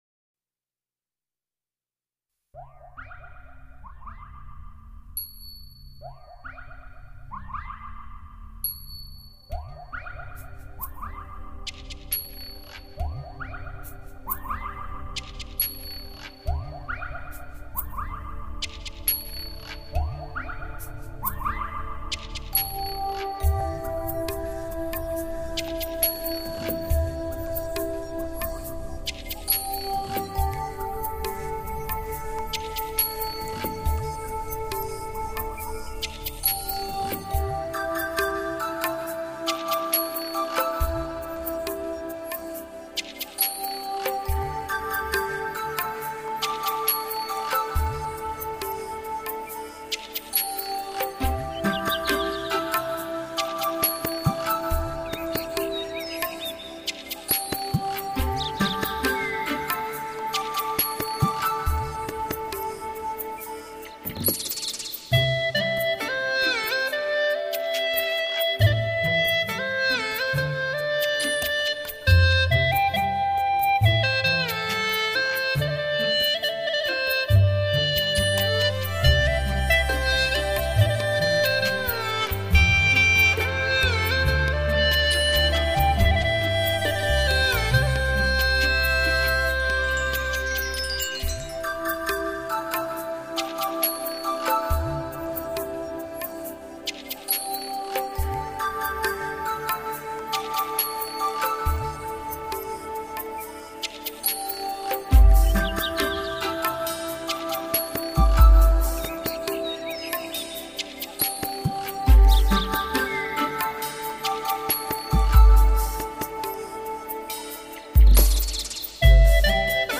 葫芦丝最新现代发烧碟，精彩的现代配器，愿味的民族风格，古典与现代完美的结合，让你感受全新的音乐风情，亲切婉转，温馨感人。
当悠扬的葫芦丝在竹林深处响起，和着多情的月光将成为你我脑海里最美的一幅图画。
优美葫芦丝 韵味浓郁 收藏极品
秀丽而不浓妆艳抹 活泼而又含蓄深情
坐上一版的沙发聆赏精美的葫芦丝曲，柔美温馨，婉转动听......